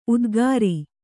♪ udgāri